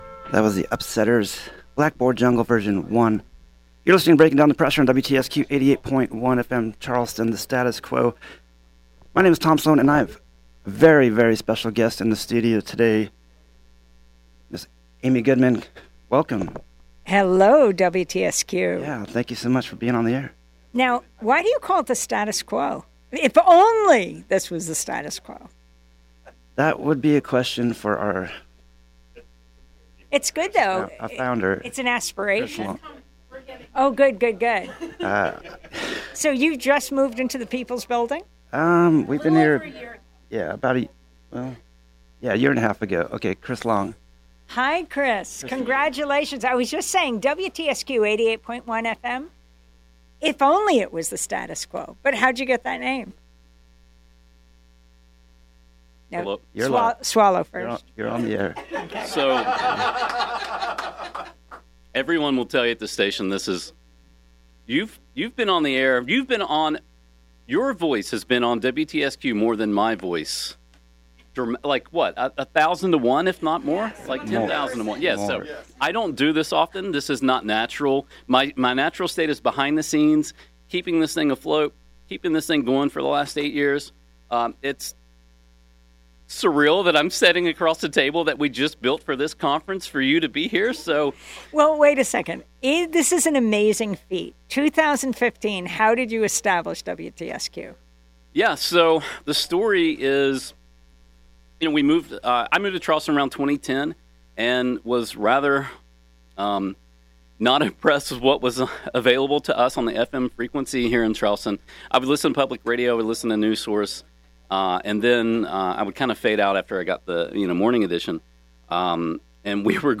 Amy Goodman, of Democracy Now!, visits WTSQ Studios during the 2023 Grassroot Radio Conference Saturday, October 21st.